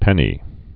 (pĕnē)